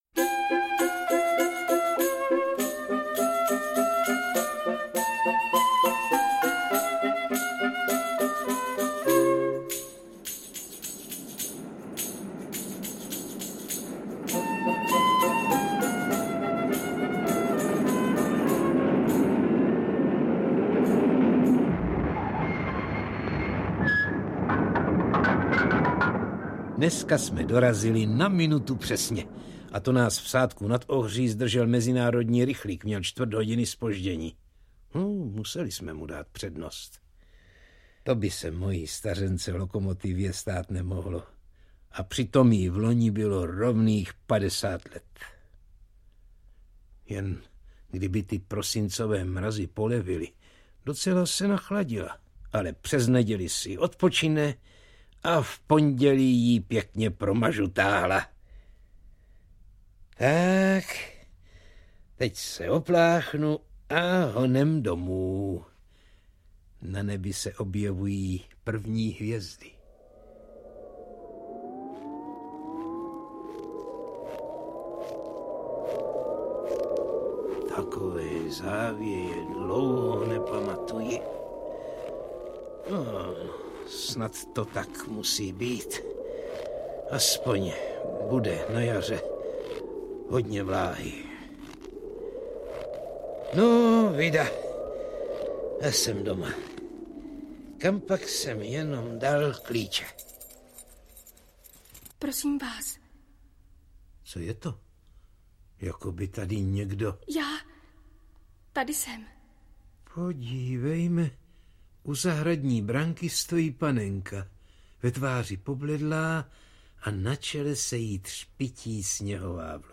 Dvanáctý díl miniserie Album pohádek "Supraphon dětem" představuje opět svým malým i velkým posluchačům výběr známých i méně známých pohádek vyprávěných i dramatizovaných